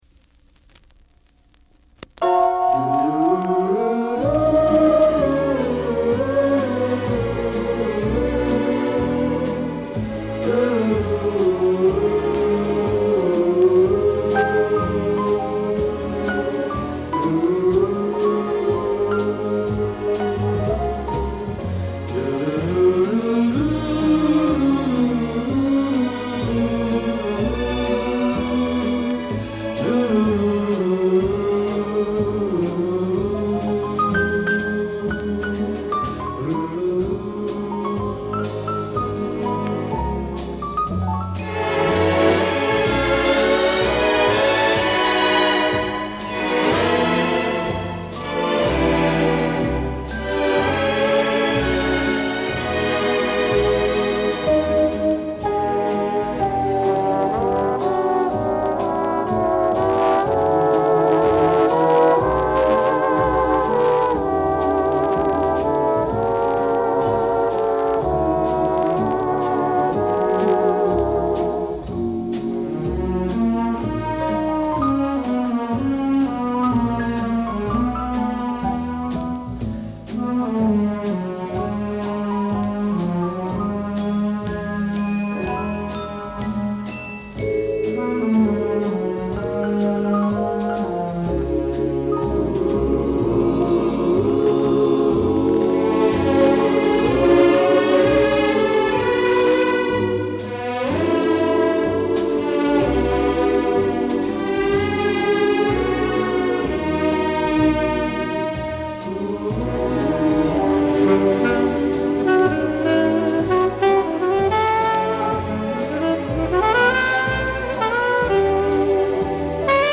45 RPM Vinyl record